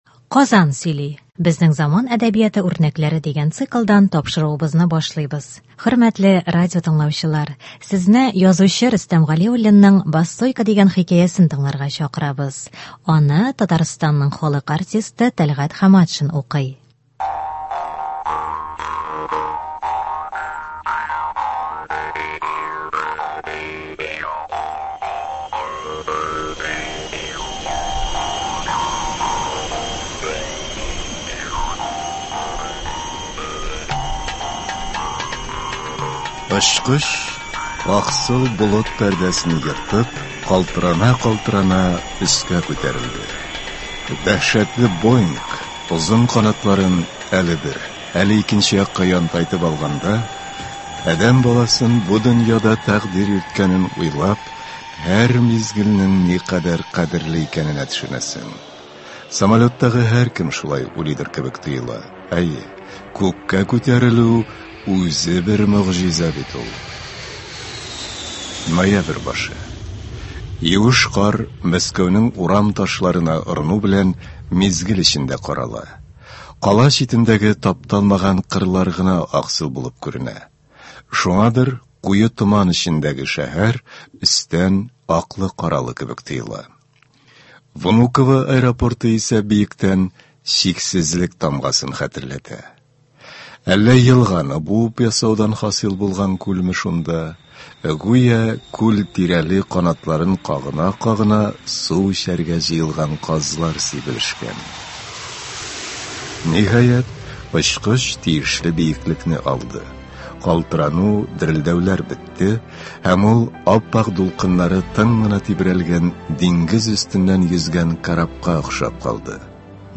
Радиотамаша.